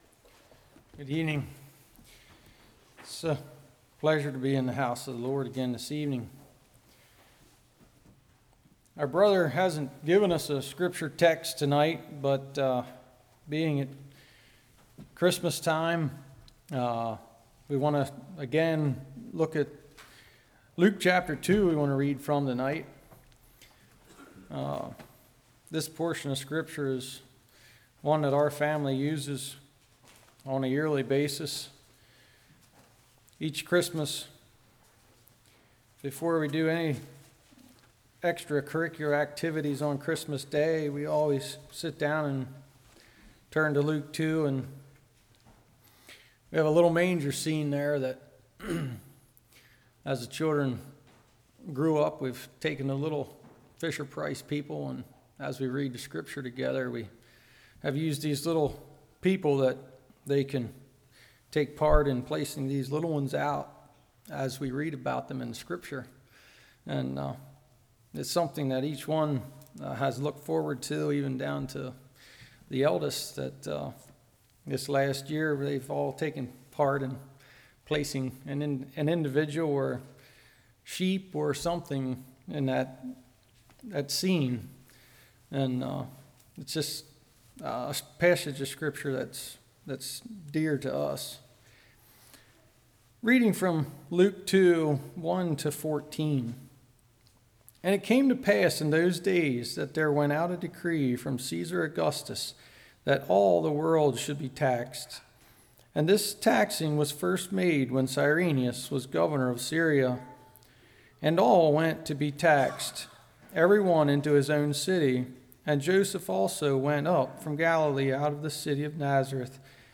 Luke 2:1-14 Service Type: Evening What To Name the Child How Do You Describe This Child?